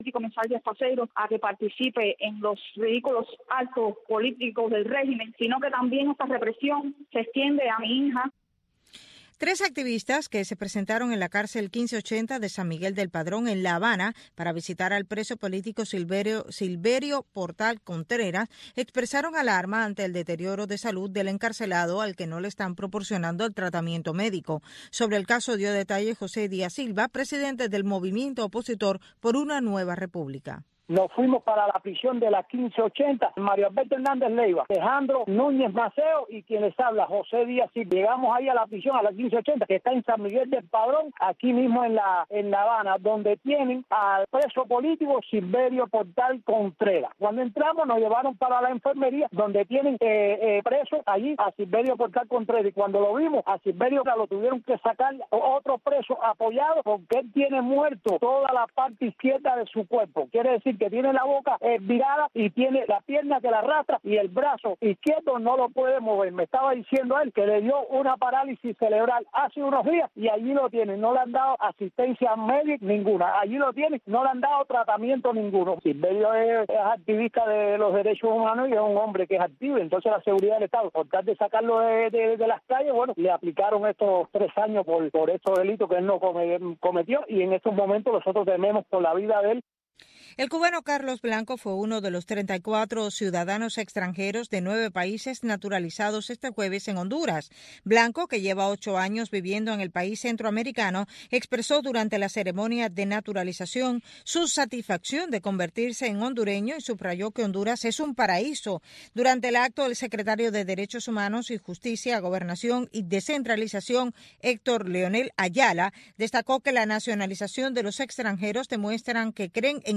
Programa humanista, preservador de buenas costumbres, reflexivo, aderezado con música y entrevistas. Las artes, el deporte, la ciencia, la política, e infinidad de tópicos, caben en este programa que está diseñado para enaltecer nuestras raíces, y para rendir tributo a esa bendita palabra que es Familia.